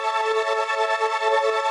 SaS_MovingPad05_140-A.wav